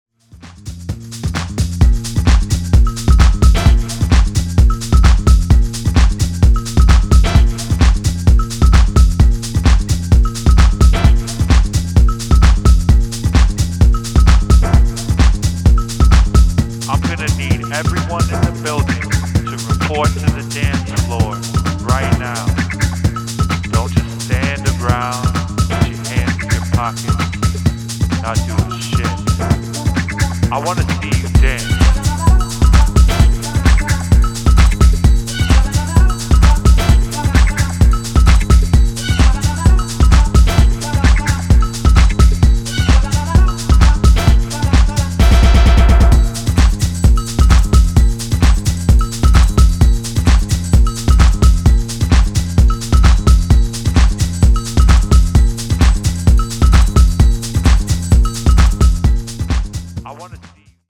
どちらもシンプルでフロア訴求力抜群な仕上がりが推せます！